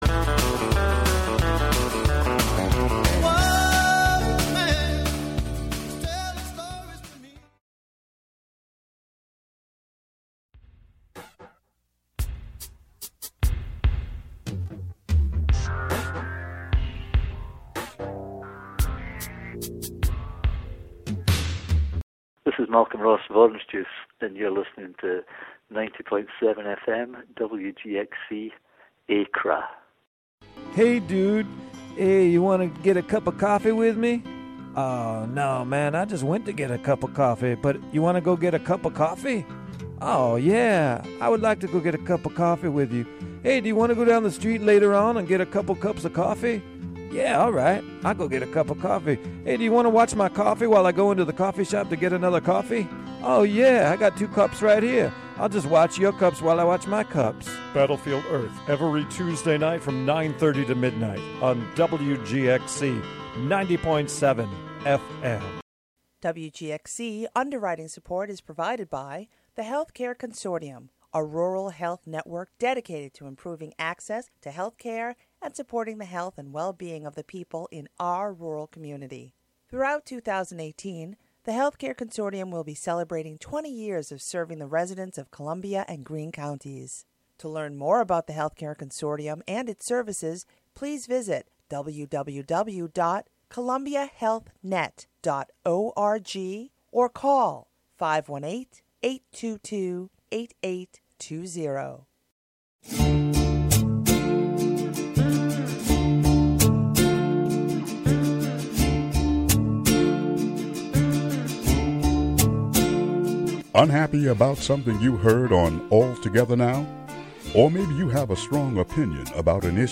Howie Hawkins, the Green Party candidate for governor of New York, is interviewed by WGXC partner station WOOC-LP.